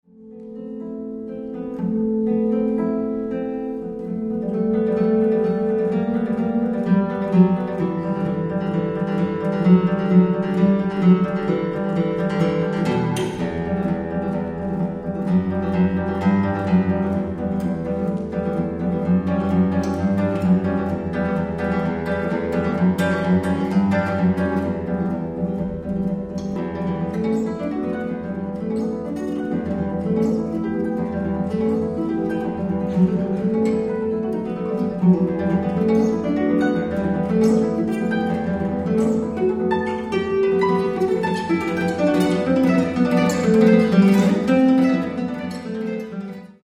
for solo guitar